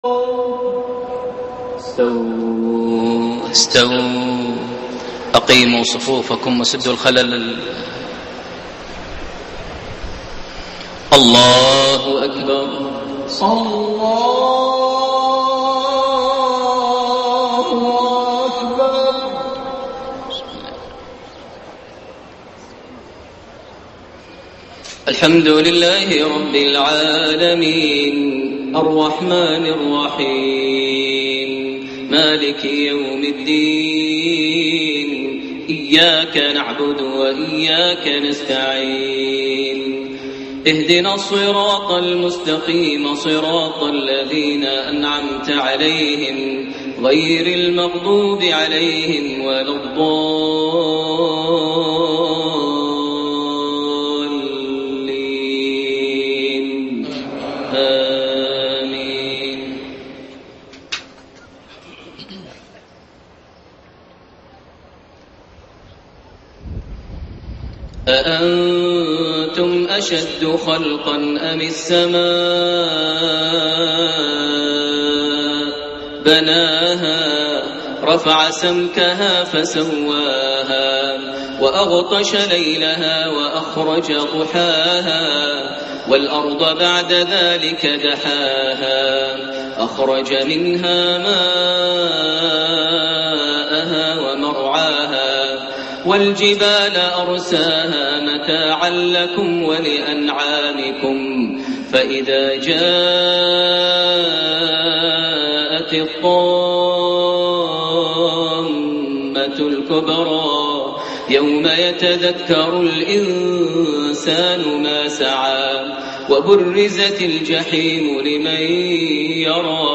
صلاة المغرب 25 جمادى الاولى 1433هـ خواتيم سورتي النازعات 27-46 و عبس 33-42 > 1433 هـ > الفروض - تلاوات ماهر المعيقلي